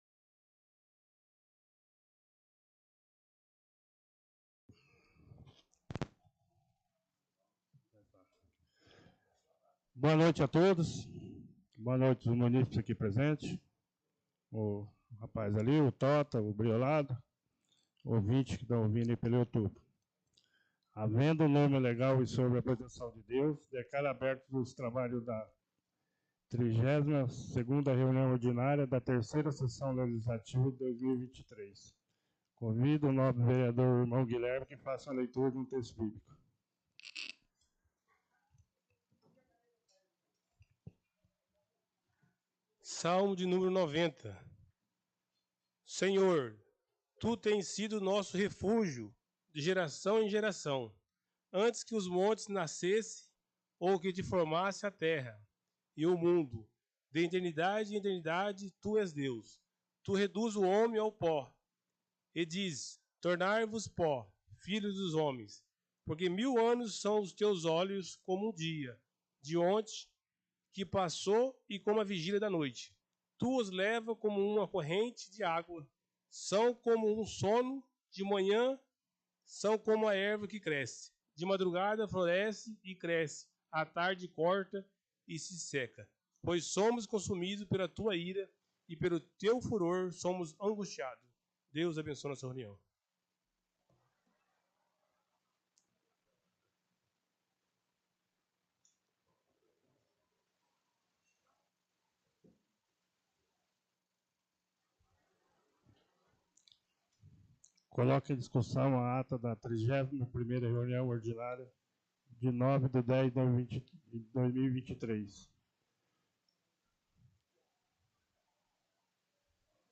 32ª Sessão Ordinária - 16-10-23.mp3